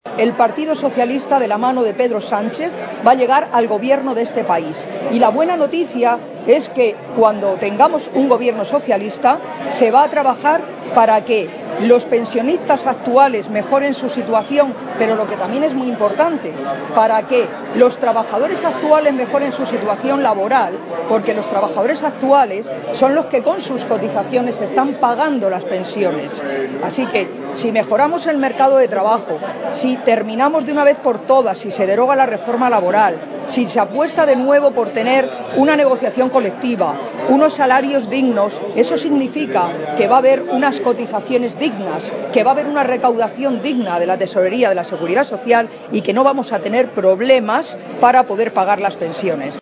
En acto público en Puertollano
Cortes de audio de la rueda de prensa